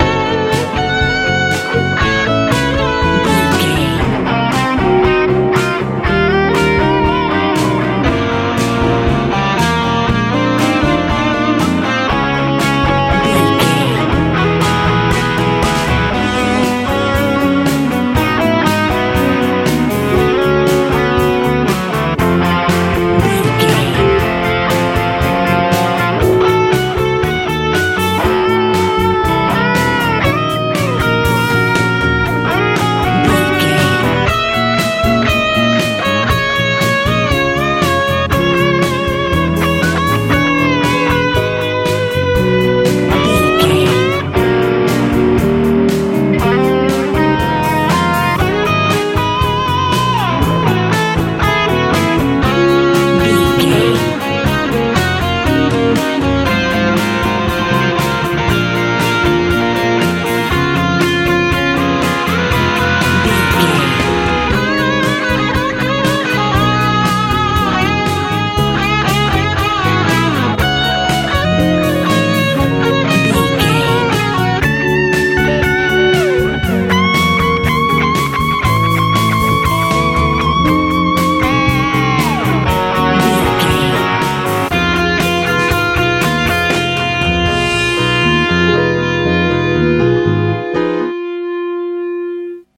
Ionian/Major
driving
magical
reflective
drums
electric guitar
piano
bass guitar
happy
organ